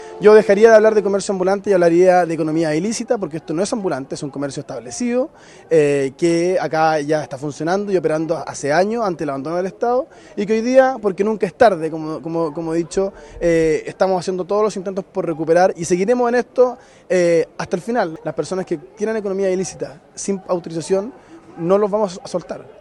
El alcalde de Independencia, Agustín Iglesias, visitó la zona y conversó con La Radio. Afirmó que su objetivo es reducir al máximo las incivilidades y el comercio ilegal, ya que este tipo de actividades suele atraer la comisión de otros delitos.